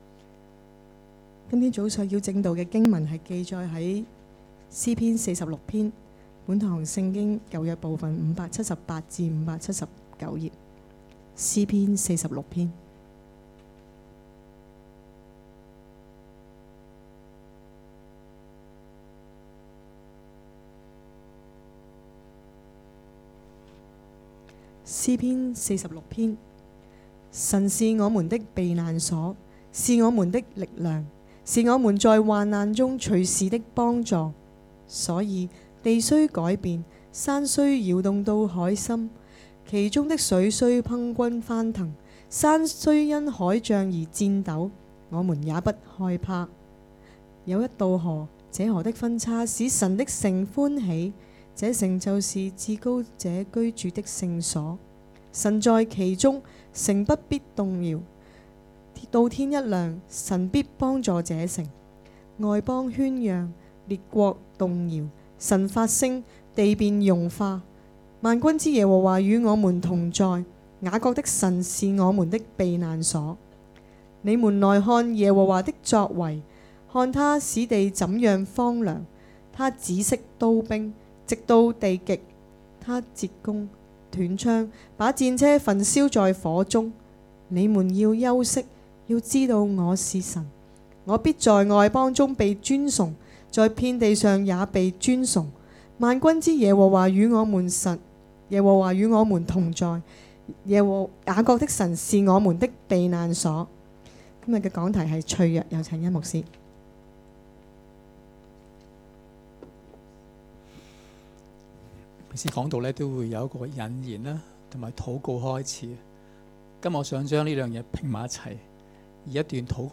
2019年7月27日及28日崇拜
2019年7月27日及28日講道